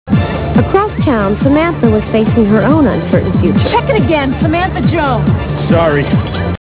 Comment: dance